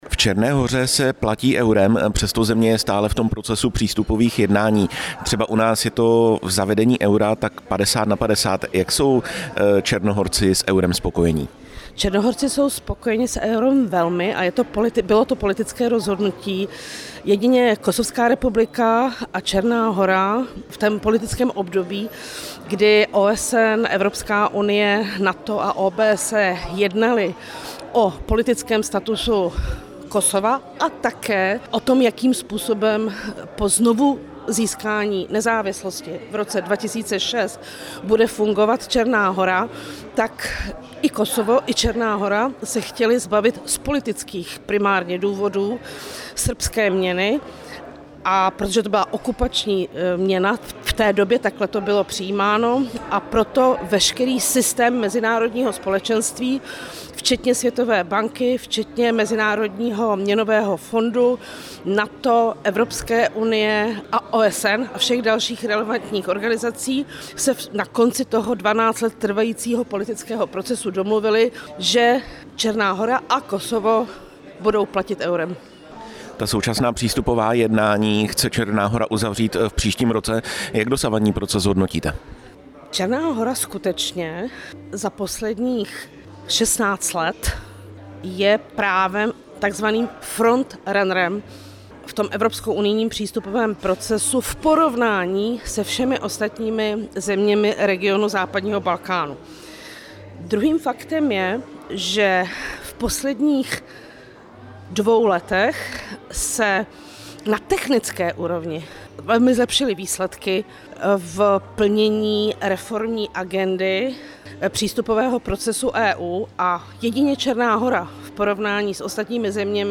Ministr zahraničí Jan Lipavský v Černé hoře jednal s prezidentem Jakovem Milatovičem, ministrem zahraničí Ervinem Ibrahimovičem a místopředsedou vlády Filipem Ivanovičem. Toto téma jsme ve vysílání Rádia Prostor rozebírali s velvyslankyní České republiky v Černé Hoře Janinou Hřebíčkovou.
Rozhovor s velvyslankyní Česka v Černé Hoře Janinou Hřebíčkovou